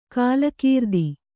♪ kālakīrdi